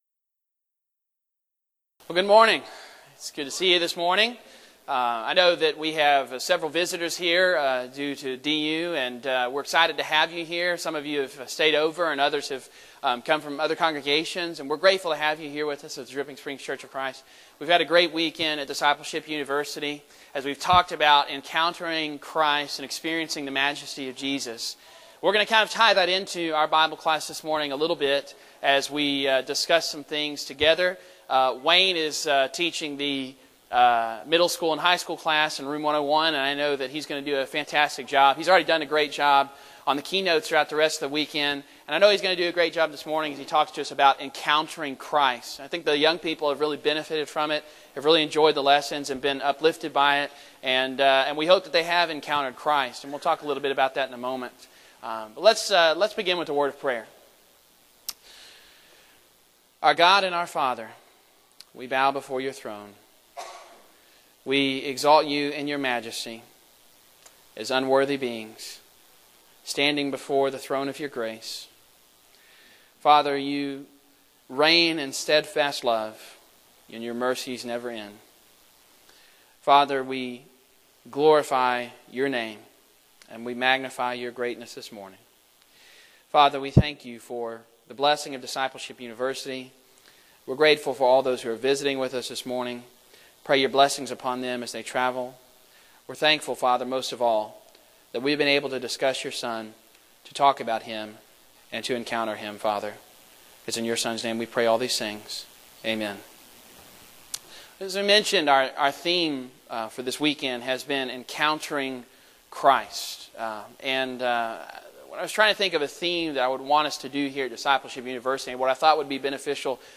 Title: Adult Bible Class
Event: Discipleship U 2016 Theme/Title: Encountering Christ: Experience the Majesty of Jesus